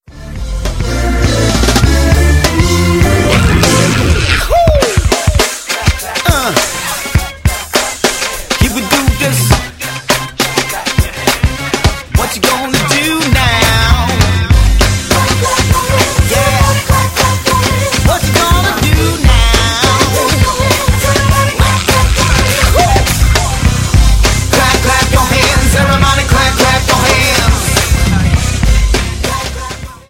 Grandiose Vokalarrangements, vibrierende
Beats und eine klare Botschaft.
• Sachgebiet: Gospel